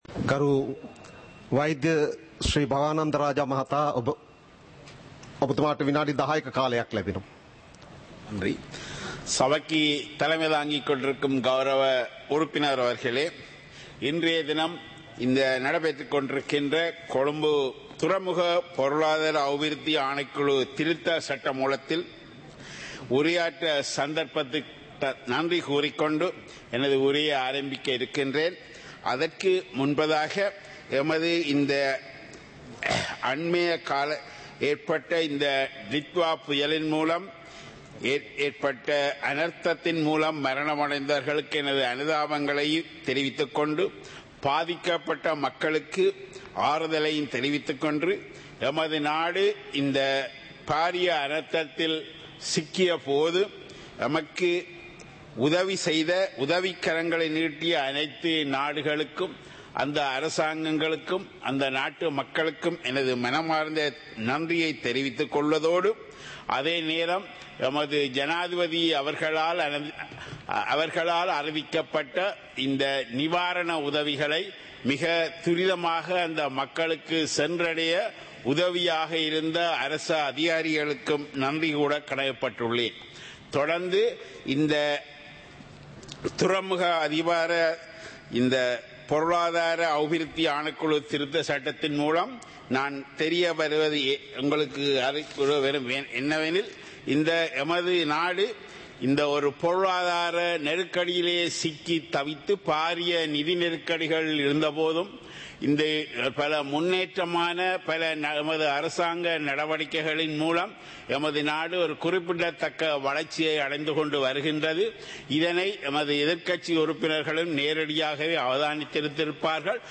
සභාවේ වැඩ කටයුතු (2026-01-07)